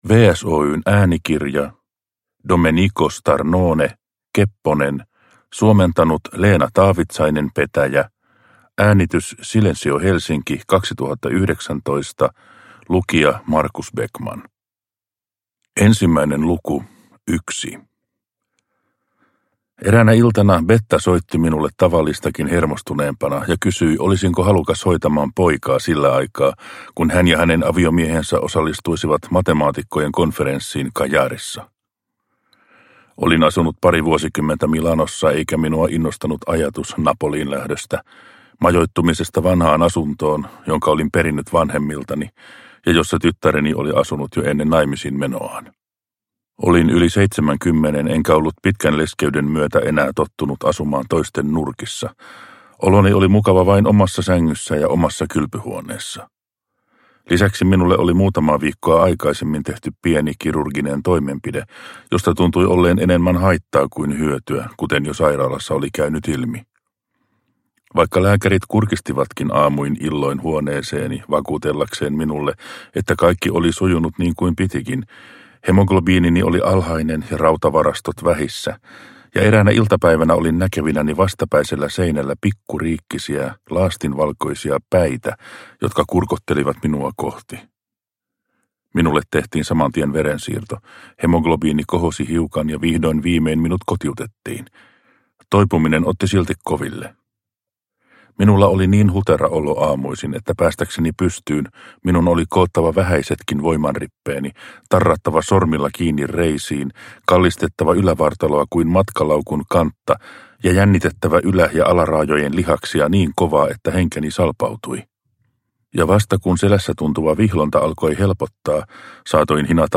Kepponen – Ljudbok – Laddas ner